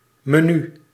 Ääntäminen
IPA: /mɛˈnʏ/